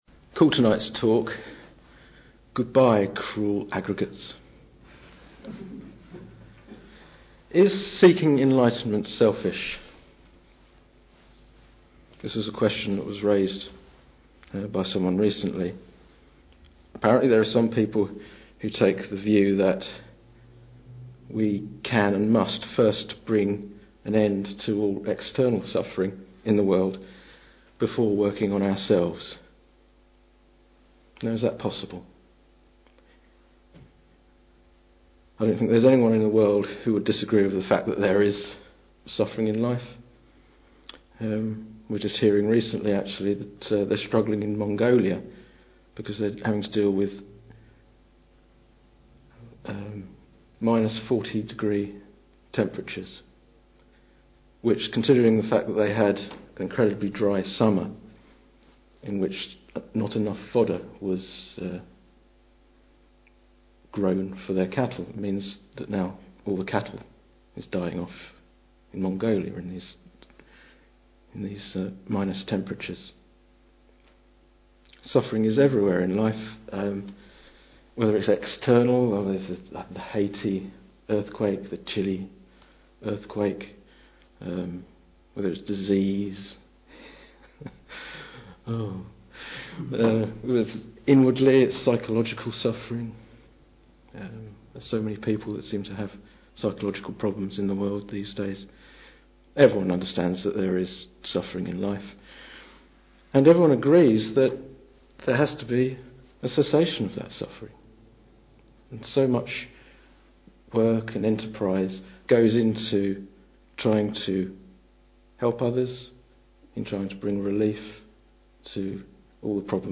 This talk was given in March 2010.